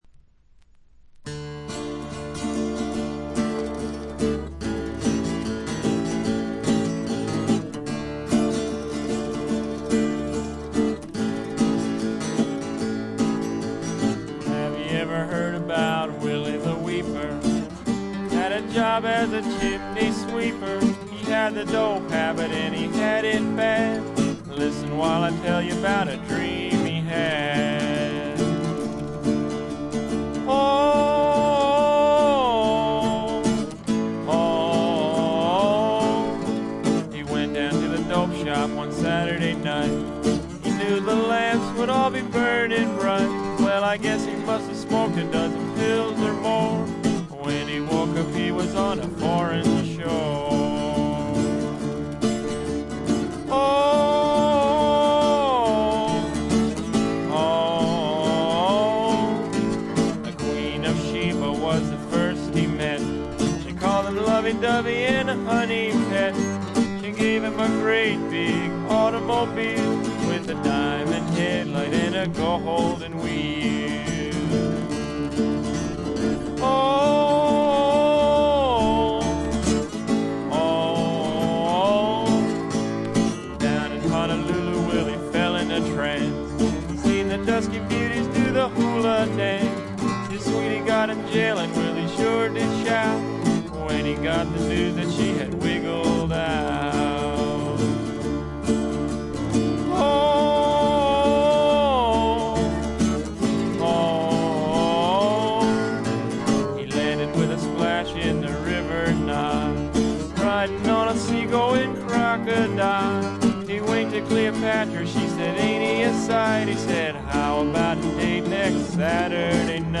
ところどころでチリプチ少々、散発的なプツ音少々。
戦前のブルース、ジャズ、ラグ、ストリングバンドといった世界をどっぷりと聴かせてくれます。
文字通りのチープで素人くささが残る演奏が愛すべき作品です。
試聴曲は現品からの取り込み音源です。